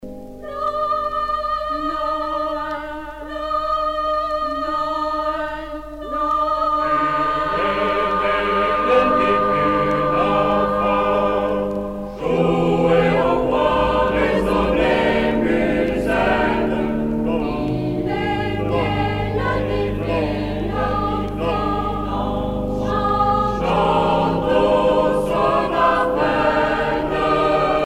Noël
Pièce musicale éditée